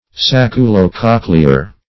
Search Result for " sacculo-cochlear" : The Collaborative International Dictionary of English v.0.48: Sacculo-cochlear \Sac`cu*lo-coch"le*ar\, a. (Anat.) Pertaining to the sacculus and cochlea of the ear.